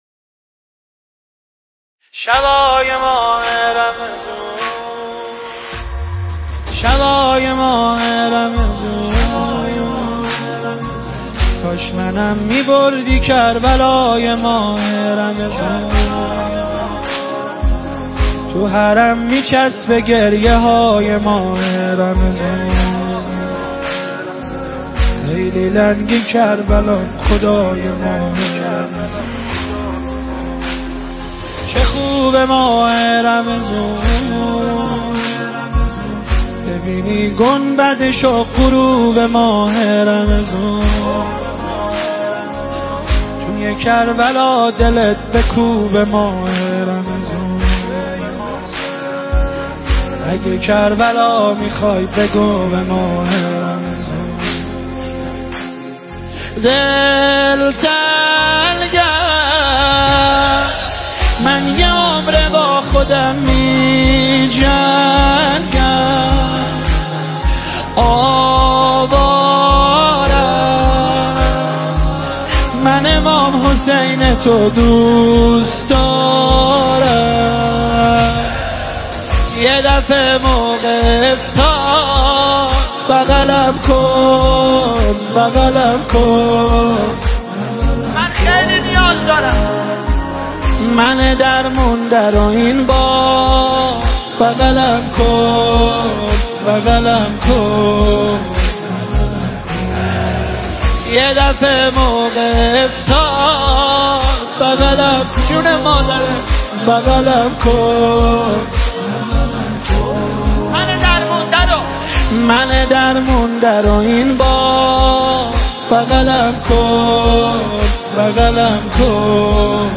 صوت نوحه